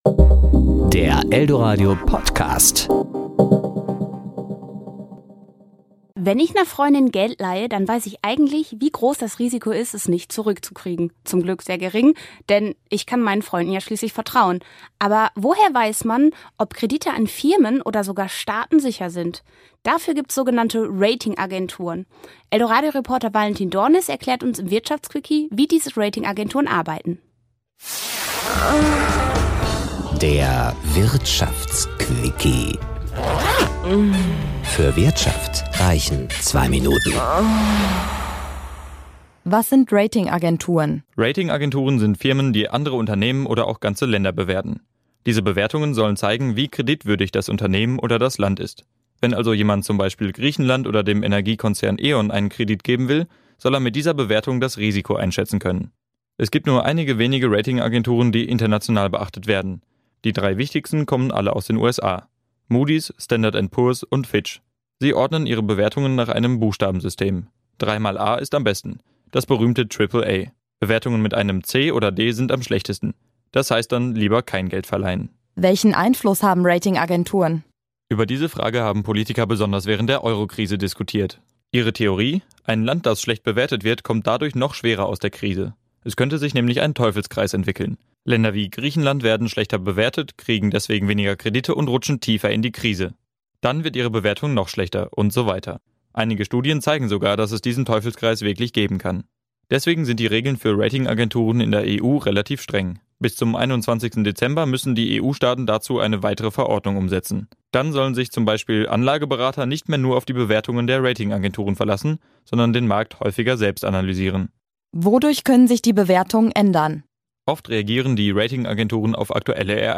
Das sind die Noten, die Ratingagenturen für Unternehmen und ganze Staaten vergeben. Komplizierte Sache, die uns unser Reporter aber einfach erklärt.